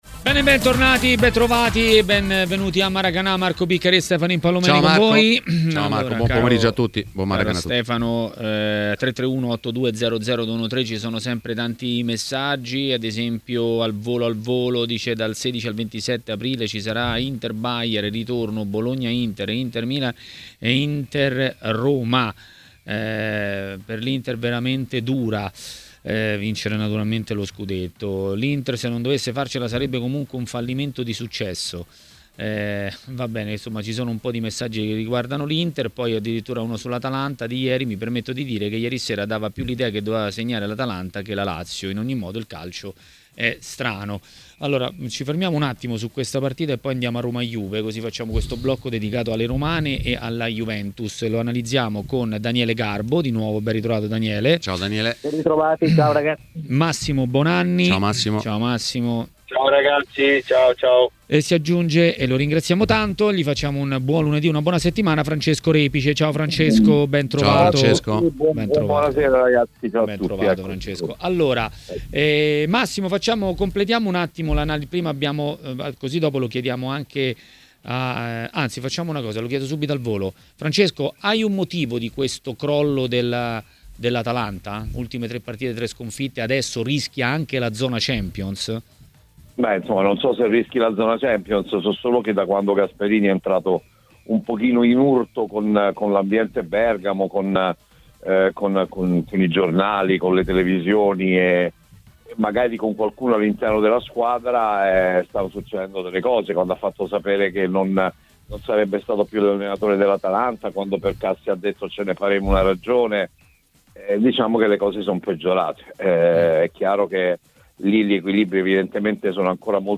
Il giornalista e voce di Tutto Il Calcio Minuto Per Minuto Francesco Repice è intervenuto a TMW Radio, durante Maracanà.